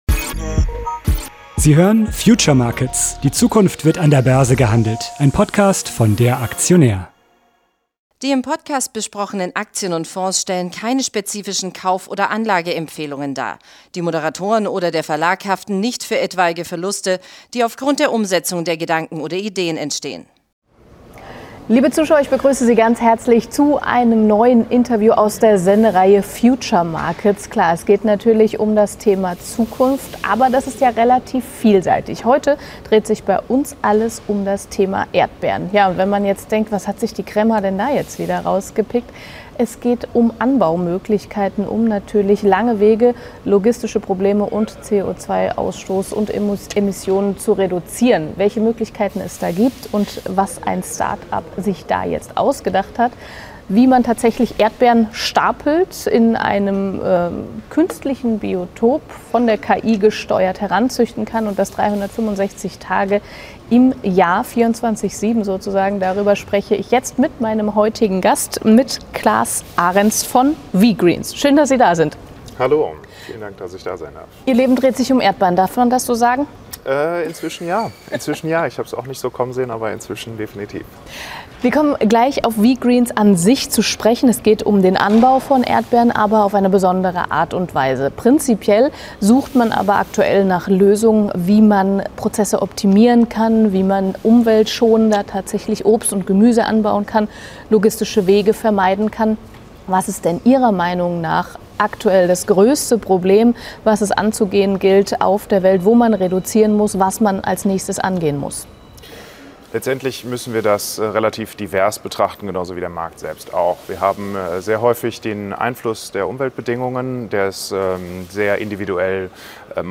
Das Gespräch wurde am 11.08.2023 an der Frankfurter Börse aufgezeichnet.